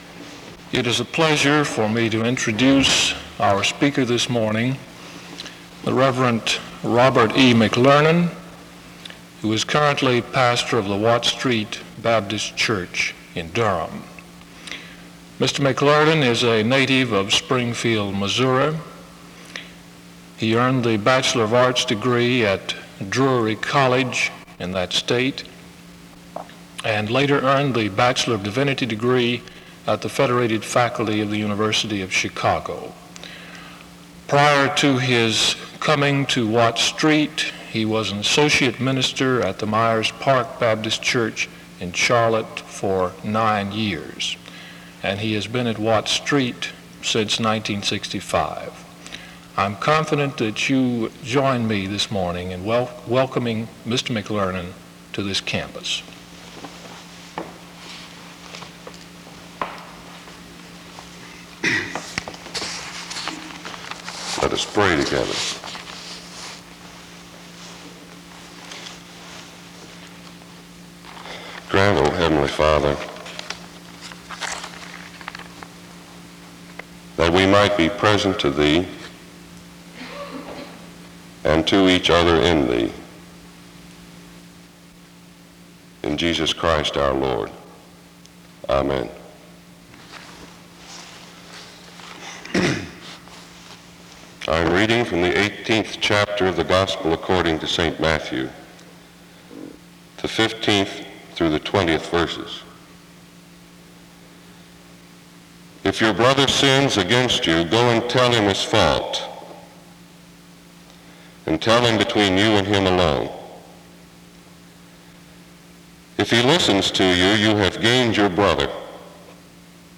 SEBTS Chapel
The service starts with an introduction to the speaker from 0:00-0:56.